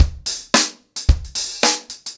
DaveAndMe-110BPM.17.wav